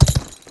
charge1.wav